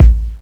INSKICK02 -R.wav